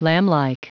Prononciation du mot lamblike en anglais (fichier audio)
Prononciation du mot : lamblike
lamblike.wav